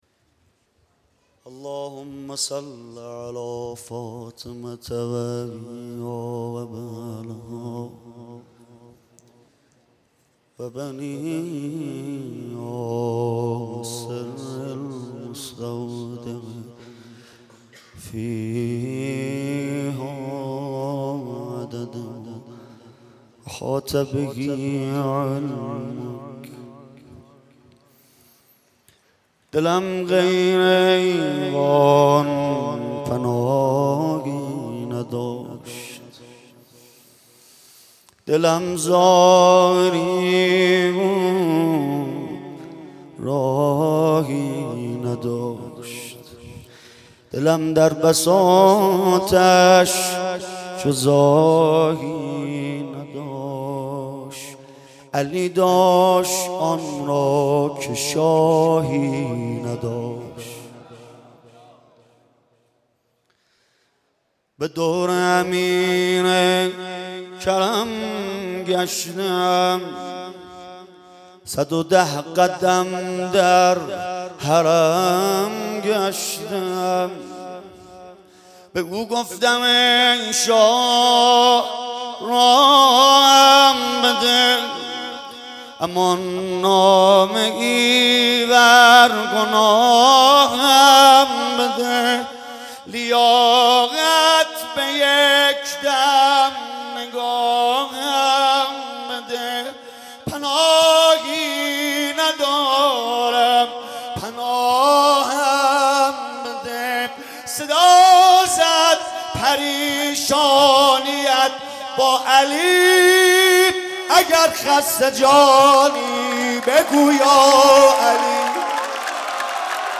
جشن میلاد حضرت علی(ع)/هیئت فدائیان حسین (ع)
با مدیحه سرایی کربلایی سید رضا نریمانی برگزار گردید